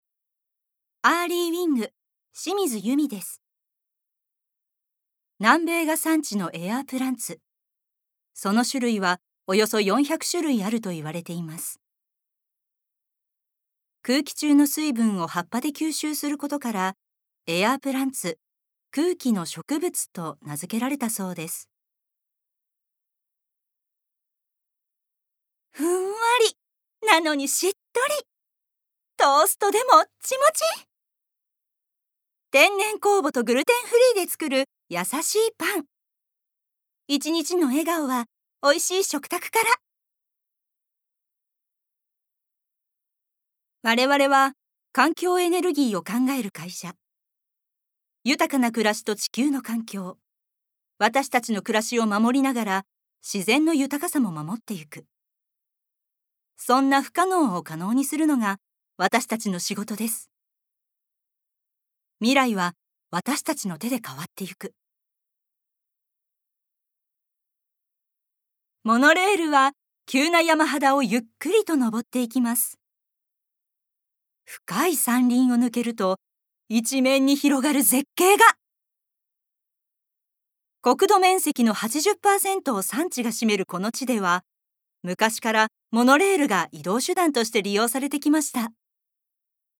Voice Sample
ナレーションALL